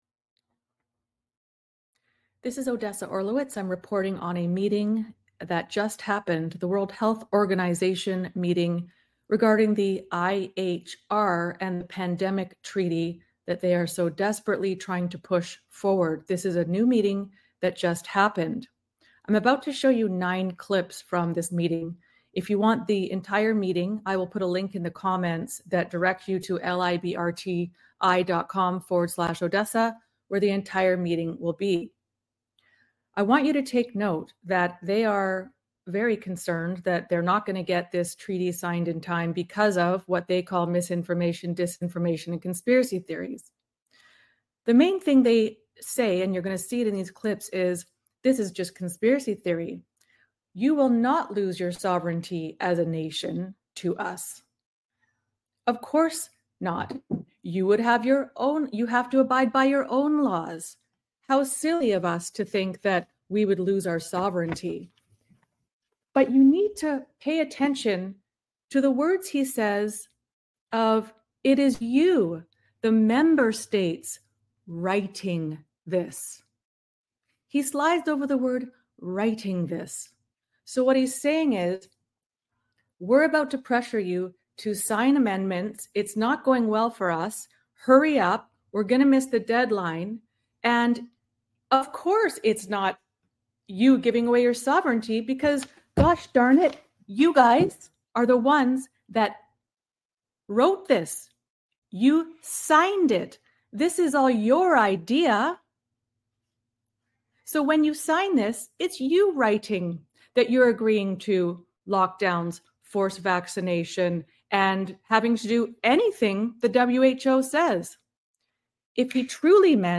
The WHO is furious that too much TRUTH has come out and is getting in the way of the pandemic treaty. My commentary at the beginning and during the meeting (clips) point out that they contradict themselves when ...